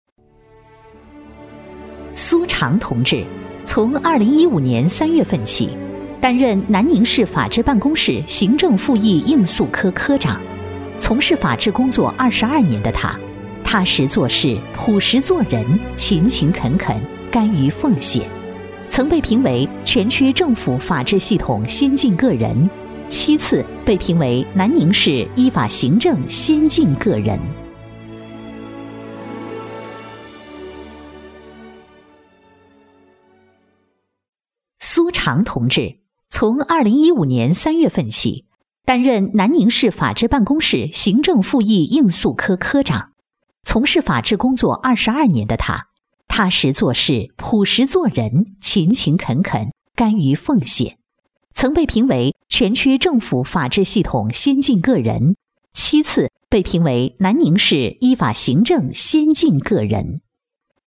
• 女S114 国语 女声 宣传片
人物介绍 沉稳温婉 亲切甜美